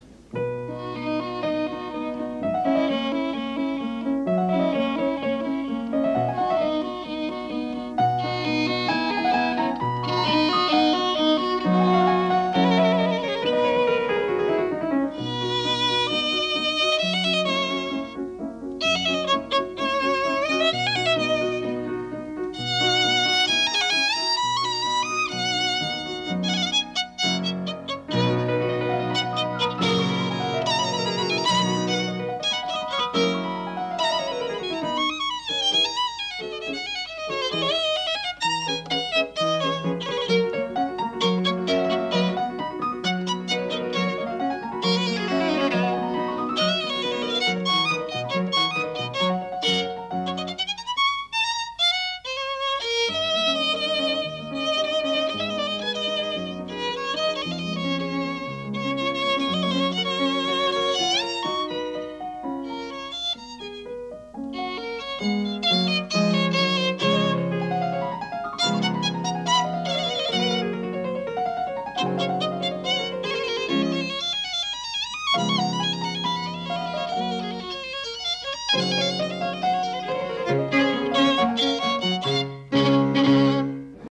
* Wolfgang Amadeus Mozart – Sonata para violín y piano, K-378
mozart-sonate-pour-violon-et-piano-k-378-allegro-moderato-audiotrimmer-com.mp3